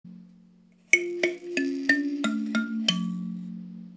ブルキナファソで作られたひょうたんで作ったカリンバです。
金属より優しくよく響く音が特徴です。
アフリカの指ピアノとも呼ばれています。指で鉄の爪をはじいて音を出します。
この楽器のサンプル音